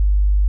50Hz.wav